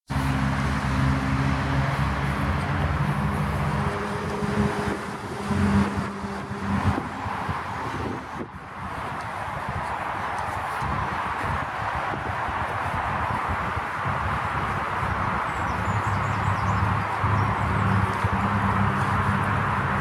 Il y a une rocade à côté
Hay una circunvalación al lado